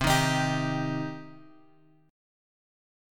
Cm chord {x 3 1 x 4 3} chord
C-Minor-C-x,3,1,x,4,3.m4a